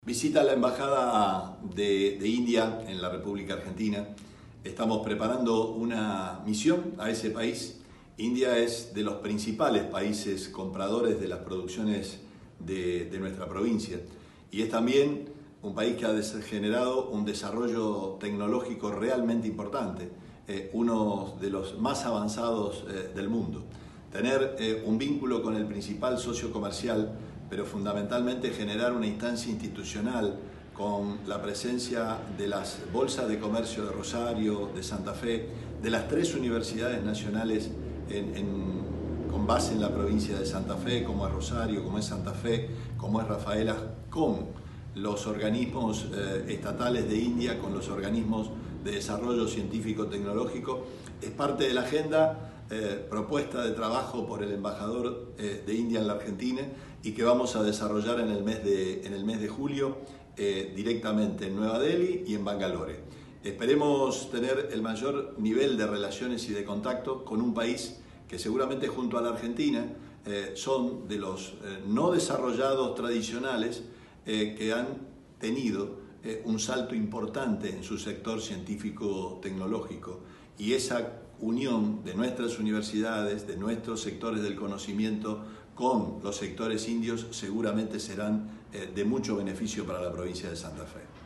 Declaraciones Perotti